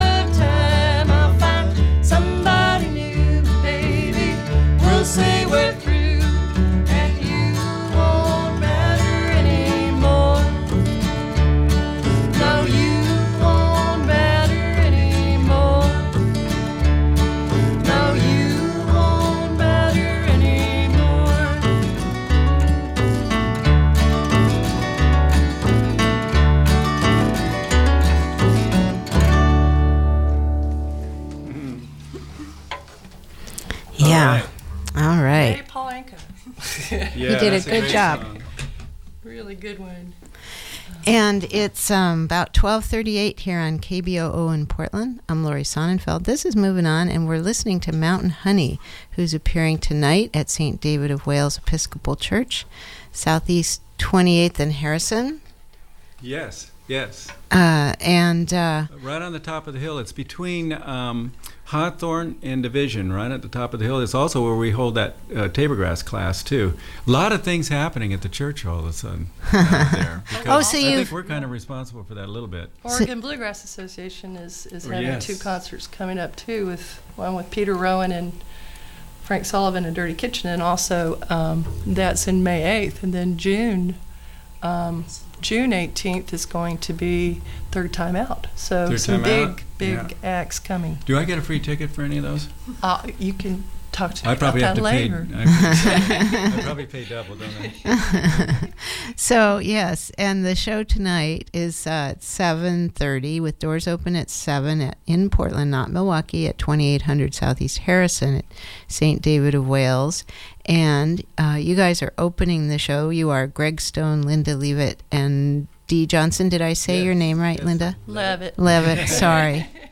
bluegrass trio